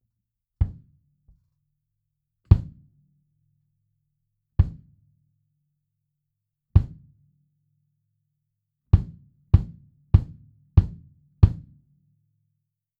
実際の録り音
バスドラム OUT
kick-out2.wav